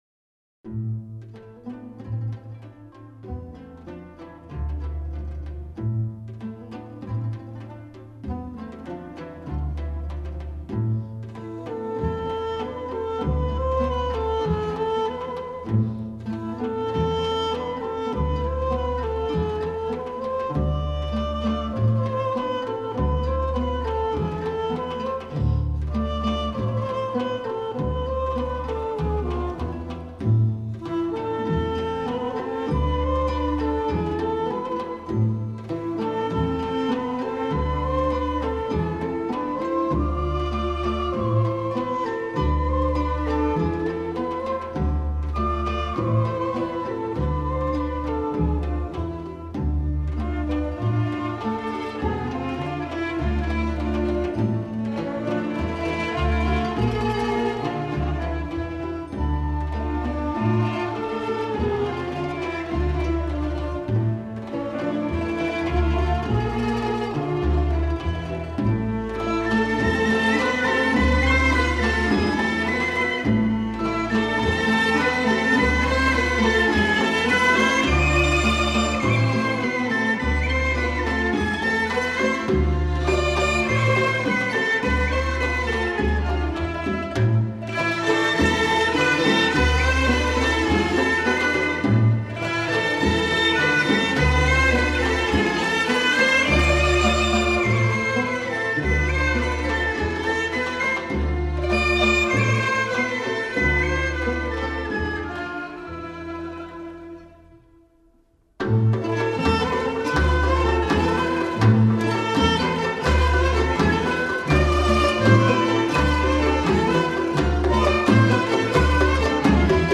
Музыка - армянская народная песня.